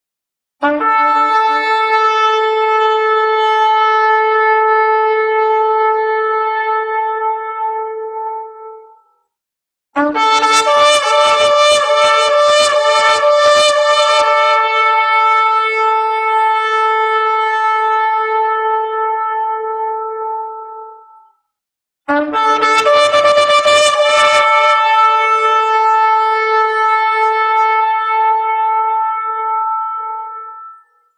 Il suono dello shofar (nella foto), antico strumento di richiamo della religione ebraica: è chiamato anche jobel,
perché ricavato dalle corna di un ariete.
shofar.mp3